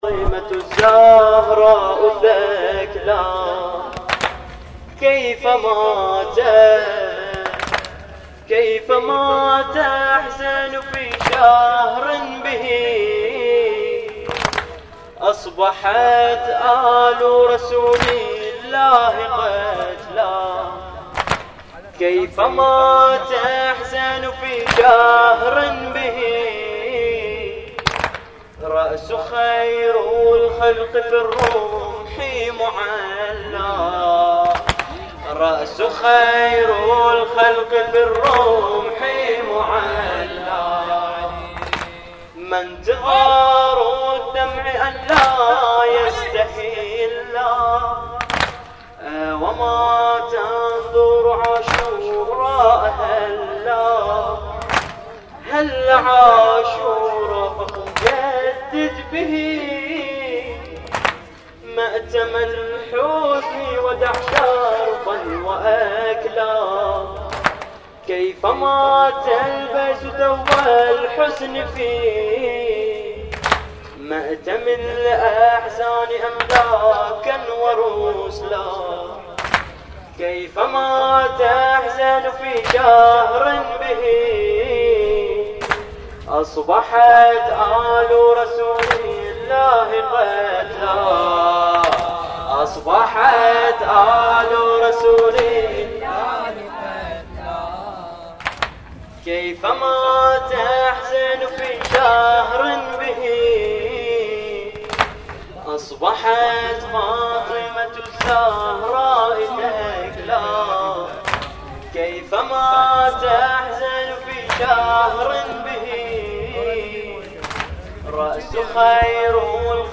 تغطية صوتية: ليلة رابع محرم 1438هـ في المأتم